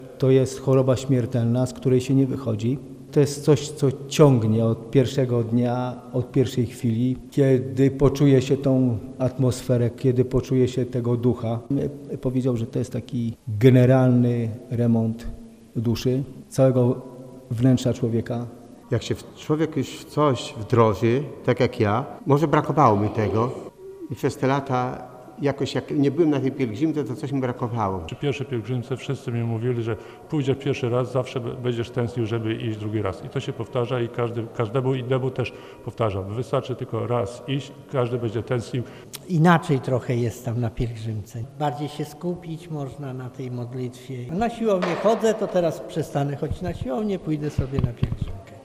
„To jak choroba śmiertelna z której się nie wychodzi, jak uzależnienie”, „To generalny remont duszy”, „Kiedy nie byłem na pielgrzymce, to czegoś mi brakowało”, „Można się bardziej skupić na modlitwie” – mówili nam pielgrzymi, spotkani podczas zapisów.